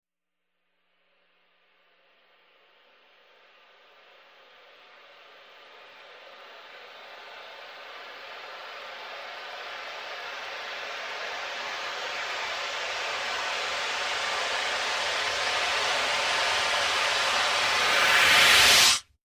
Strange Reversed Air Releases Vary In Pitch, X10